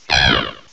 -Replaced the Gen. 1 to 3 cries with BW2 rips.
uncomp_eiscue_noice_face.aif